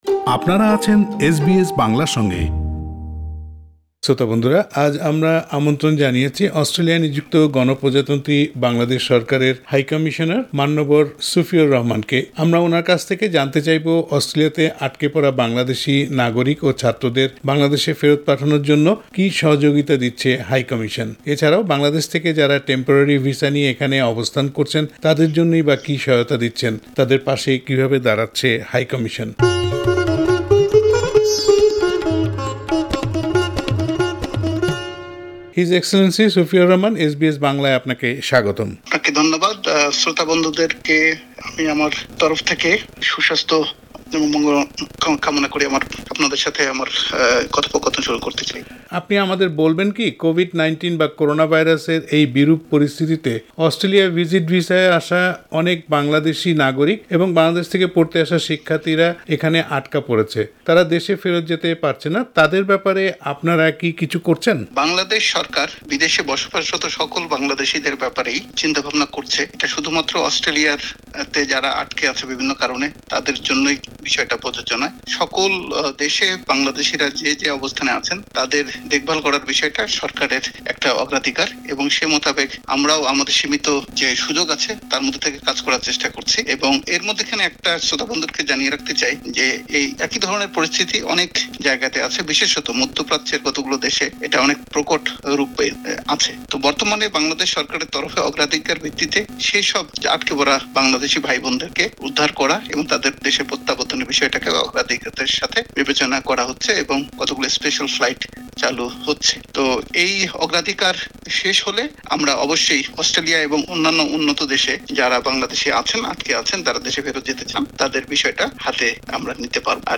এ সব কিছু নিয়ে এস বি এস বাংলার সঙ্গে কথা বলেছেন অস্ট্রেলিয়াতে নিযুক্ত বাংলাদেশ সরকারের হাই কমিশনার মান্যবর সুফিউর রহমান। রাষ্ট্রদূত সুফিউর রহমানের সাক্ষাৎকারটি শুনতে উপরের অডিও লিংকটিতে ক্লিক করুন।